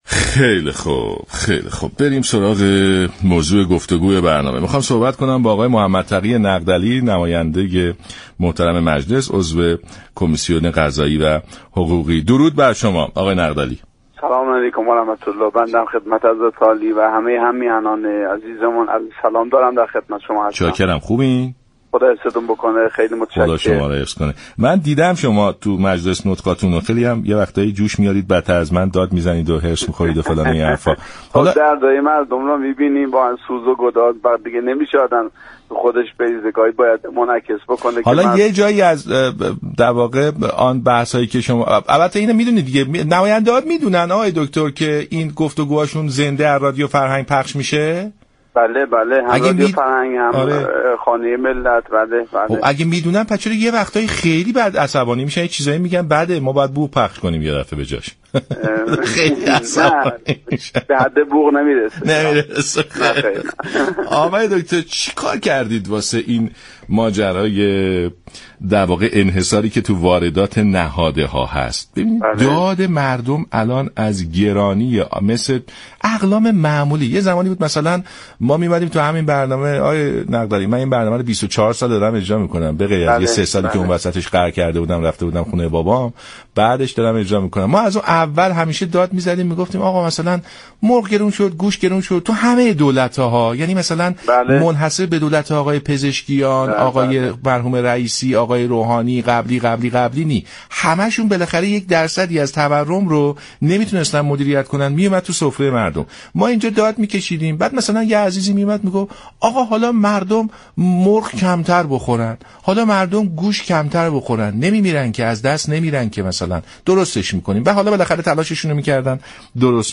عضو كمیسیون قضایی و حقوقی مجلس در برنامه سلام‌صبح‌بخیر گفت: نمی‌خواهم بگویم در واردات نهاده‌های دامی انحصاری وجود ندارد؛ اما نابسامانی‌های امروز كمتر ناشی از این موضوع است.